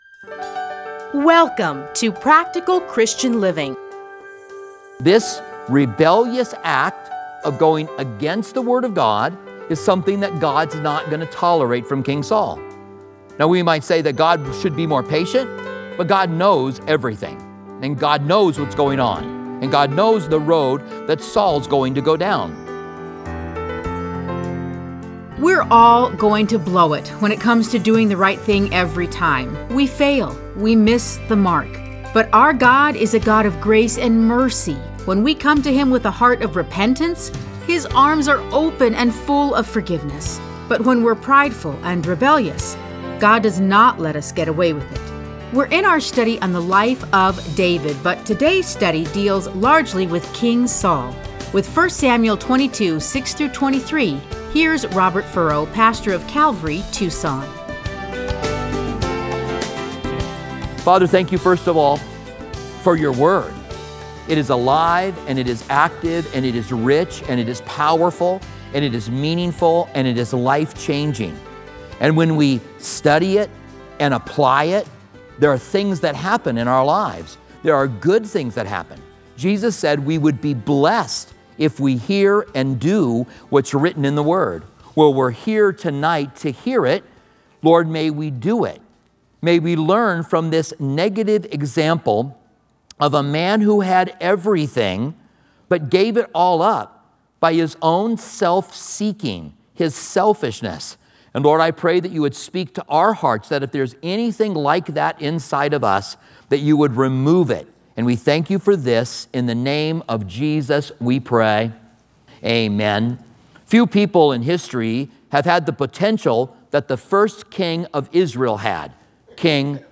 Listen to a teaching from 1 Samuel 22:6-23.